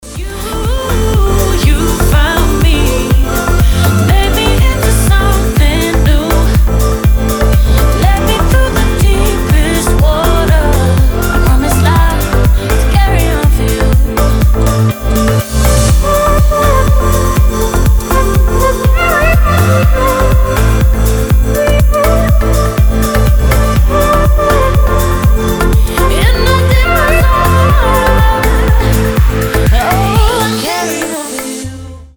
женский вокал
милые
саундтреки
Dance Pop
tropical house
вдохновляющие
добрые